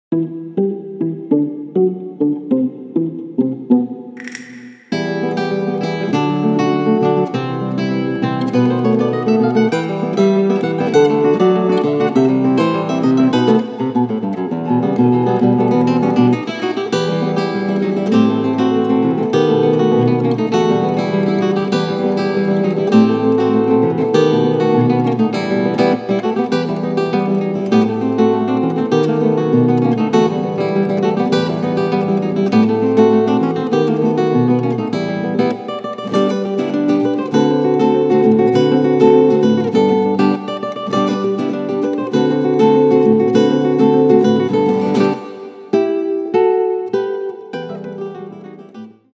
- Pieces for guitar duo -